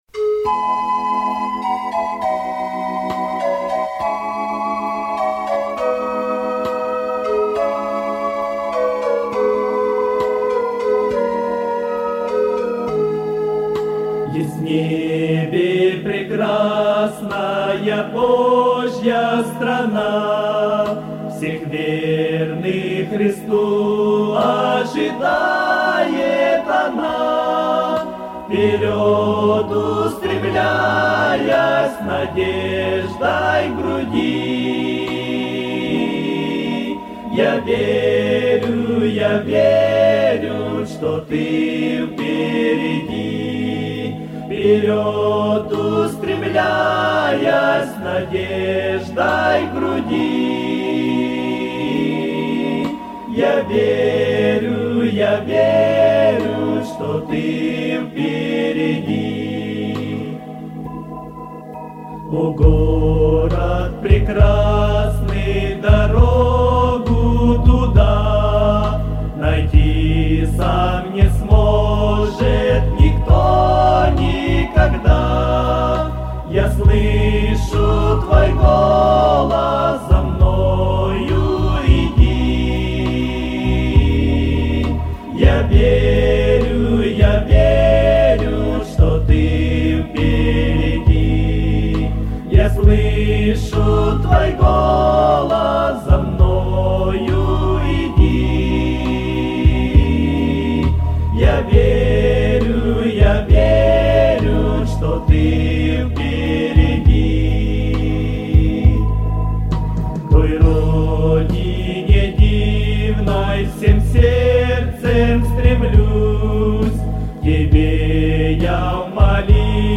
204 просмотра 723 прослушивания 38 скачиваний BPM: 76